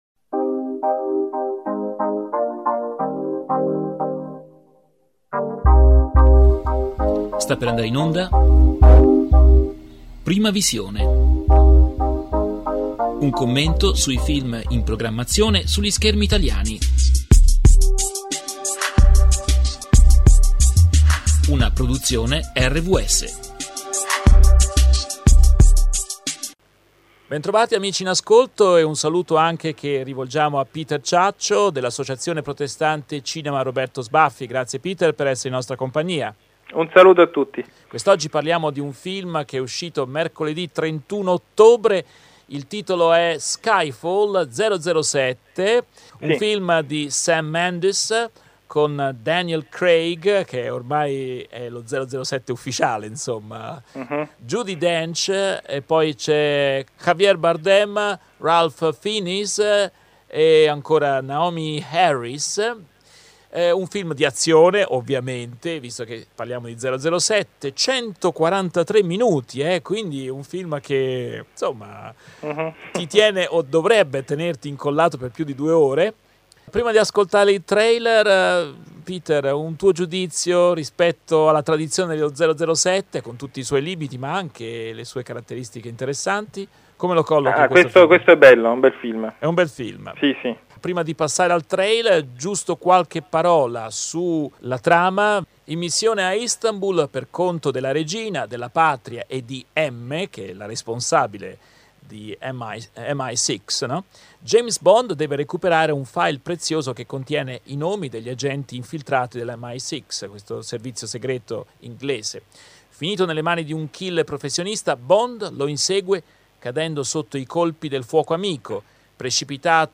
Recensione del film “Skyfall”, regia di Sam Mendes, con Daniel Craig, Judi Dench, Javier Bardem, Ralph Fiennes, Naomie Harris.